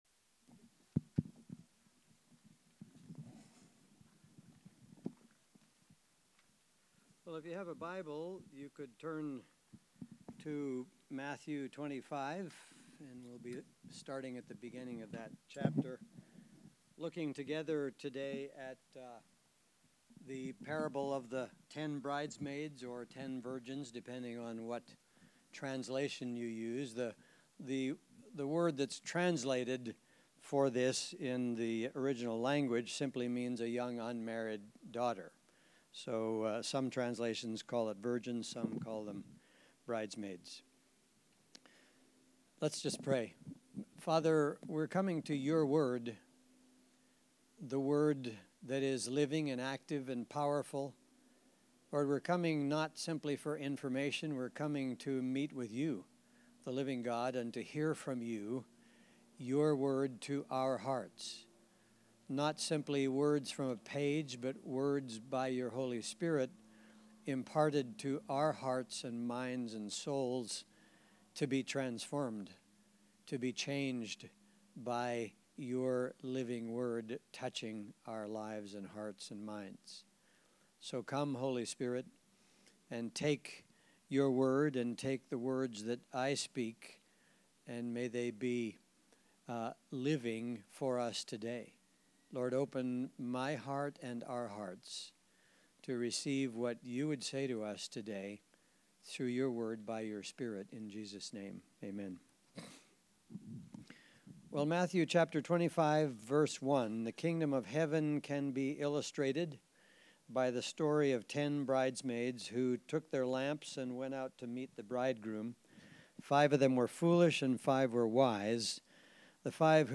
Sunday Messages | Transformation Church